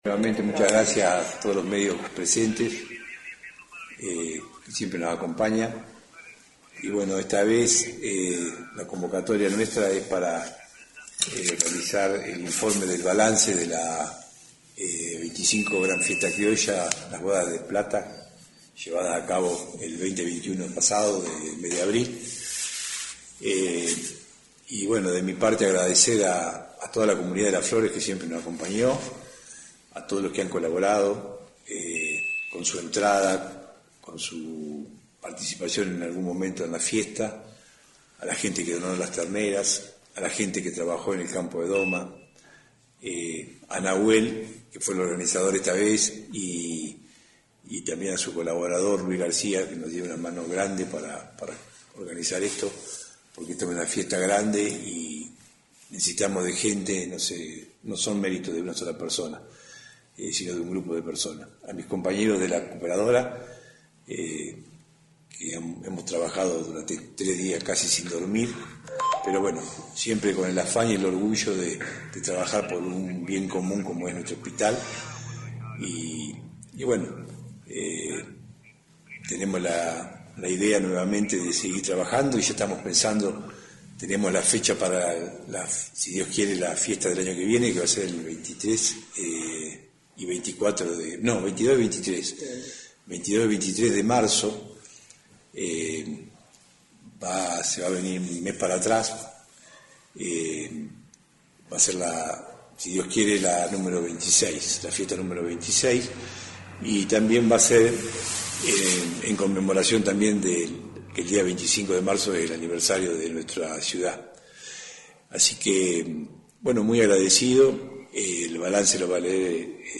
(ver balance) En la mañana de este jueves la Asociación Cooperadora del Hospital convocó a la prensa local para dar a conocer -como hace cada año- el balance de la última Fiesta Criolla realizada el 20 y 21 de abril pasado.
Conferencia-Hospital-Cooperadora-1.mp3